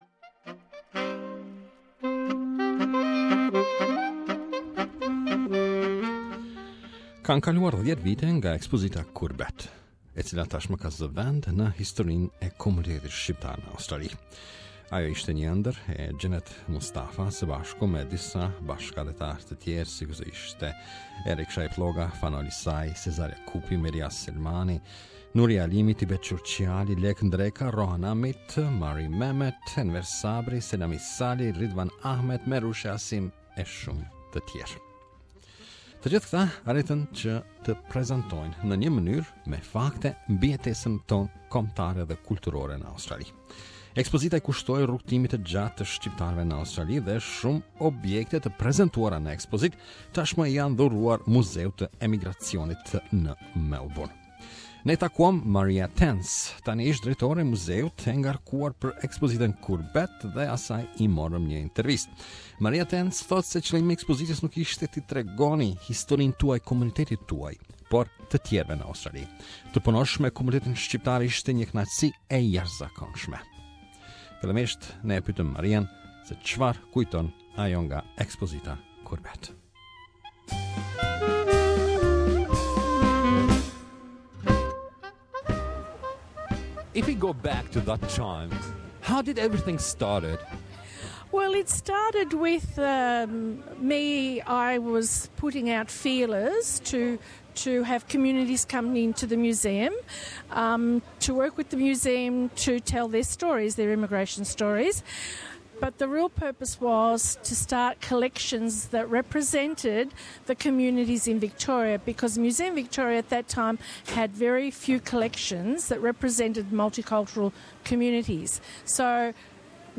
Ne i morëm asaj një intervistë.